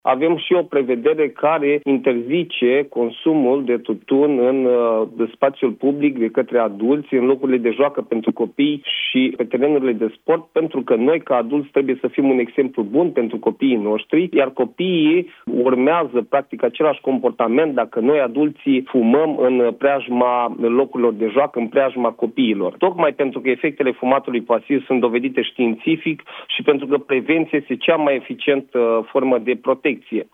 Ștefan Tănasă, deputat USR: „Pentru că noi, ca adulți, trebuie să fim un exemplu bun pentru copiii noștri”